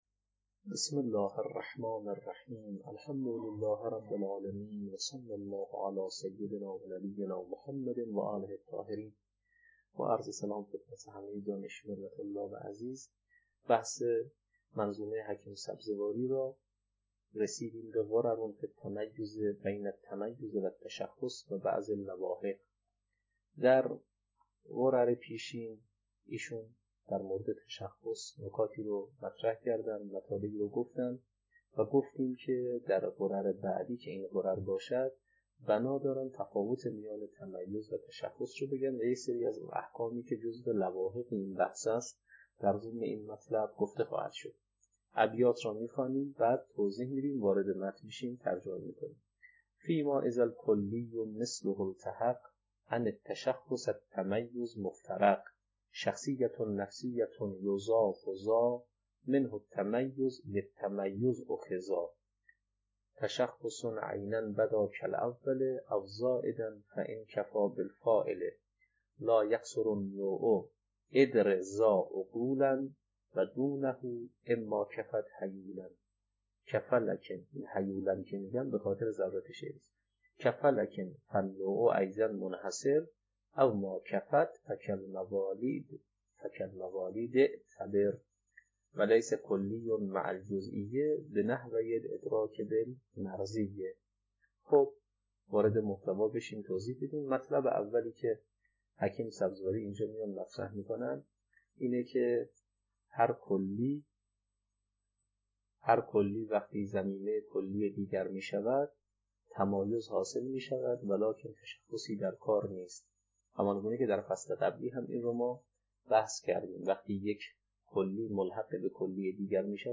تدریس کتاب شرح منظومه